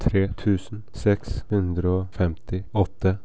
It has simply been resolved by saying hundred-and in the recording of 100.